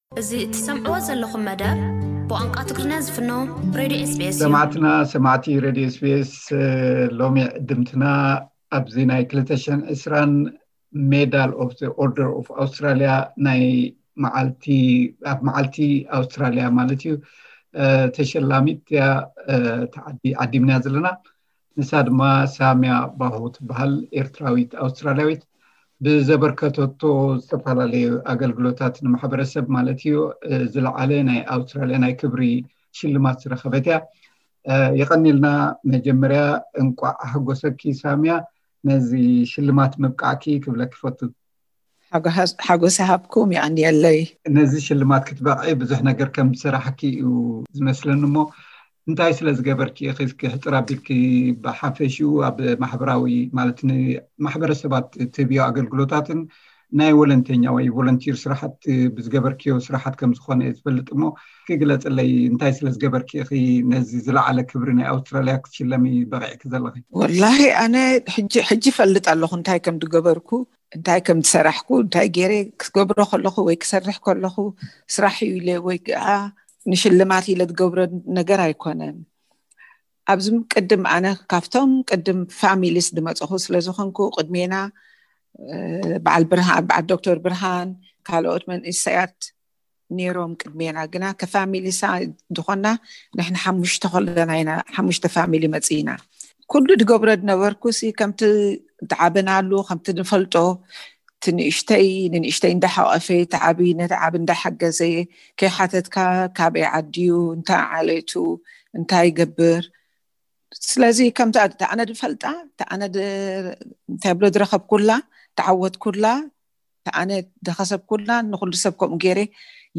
ምስ ኣ ዝተገብረ ሓጺር ዕላል።